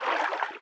Sfx_creature_symbiote_swim_slow_03.ogg